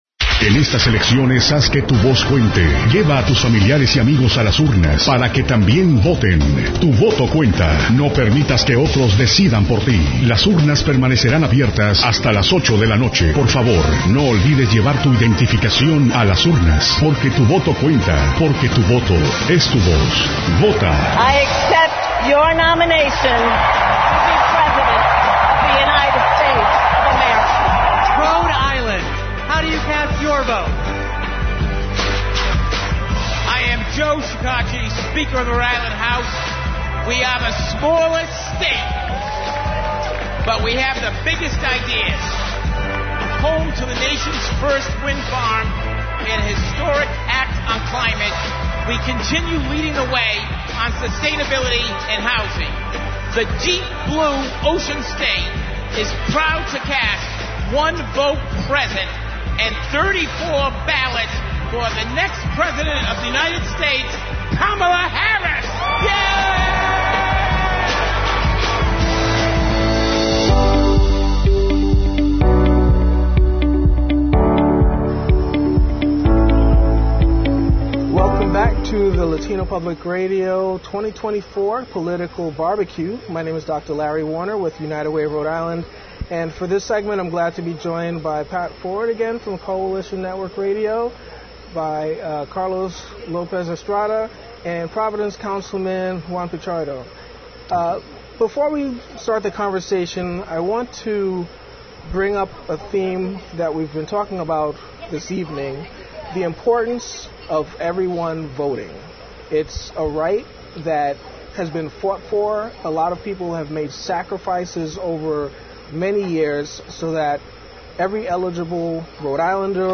Commentaries with Guests at LPR’s Political BBQ 2024